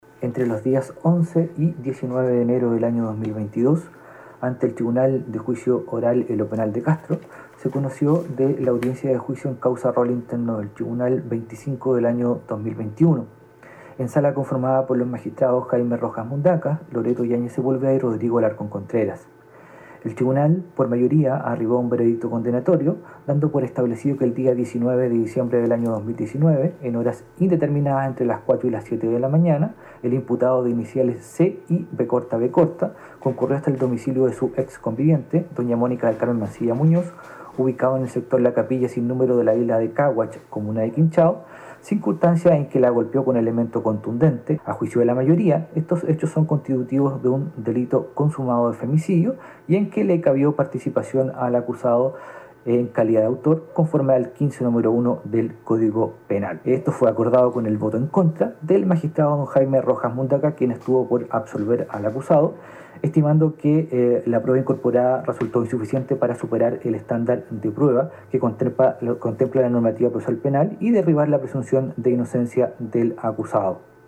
El juez del Tribunal Oral en Lo Penal de Castro, Rodrigo Alarcón, entregó algunos de los aspectos que se tomaron en cuenta para llegar a este fallo de mayoría.